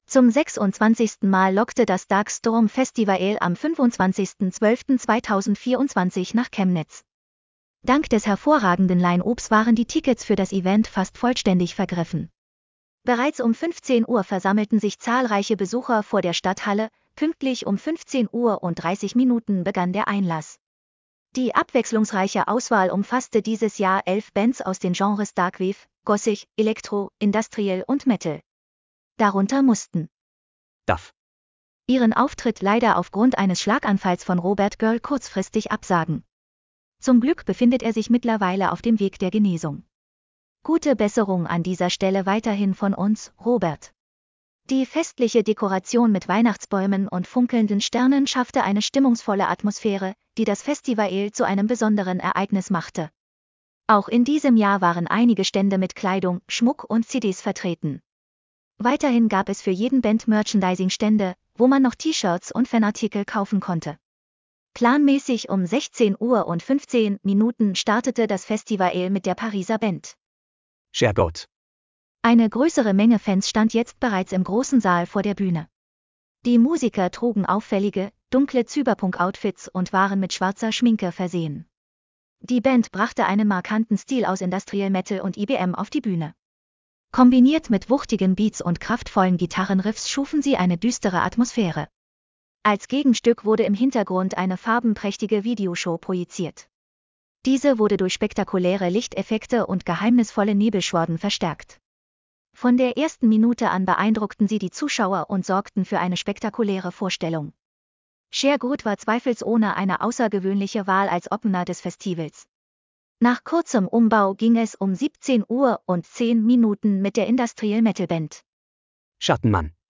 26. DARK STORM FESTIVAL - Chemnitz, Stadthalle (25.12.2024)
Die abwechslungsreiche Auswahl umfasste dieses Jahr 11 Bands aus den Genres Darkwave, Gothic, Elektro, Industrial und Metal.